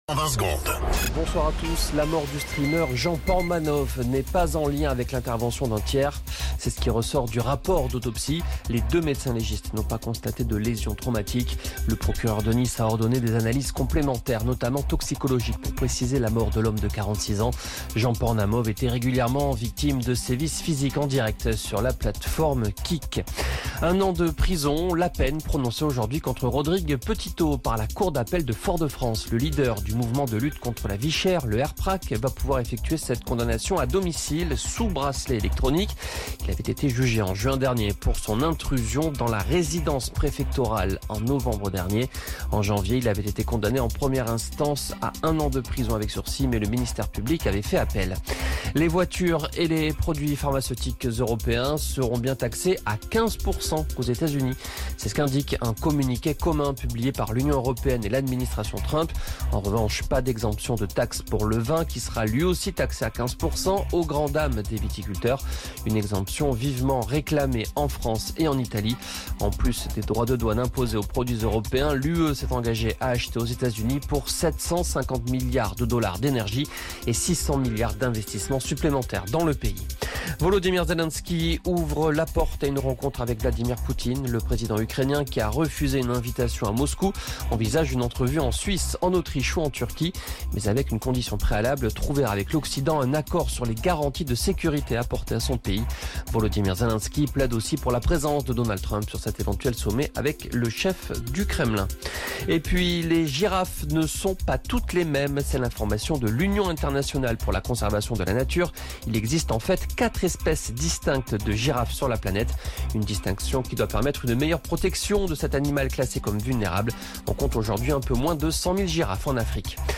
Flash Info National 21 Août 2025 Du 21/08/2025 à 17h10 .